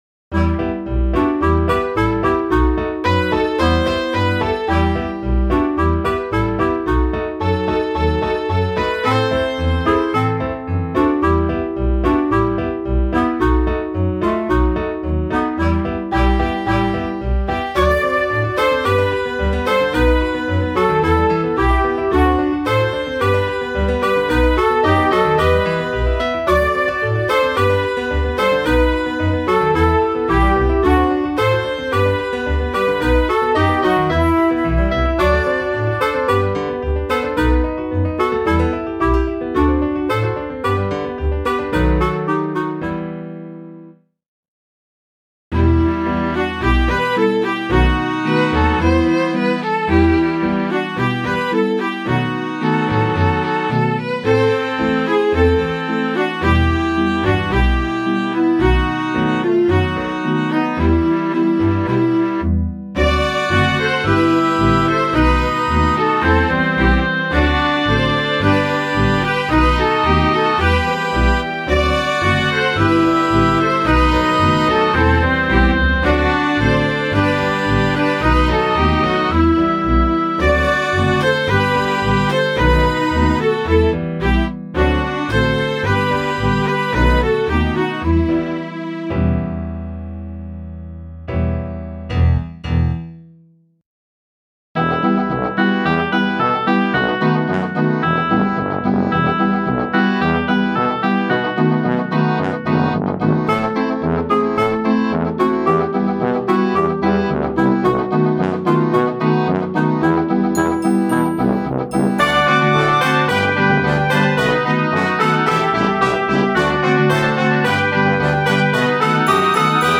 Russische Lieder
Instrumentalsätze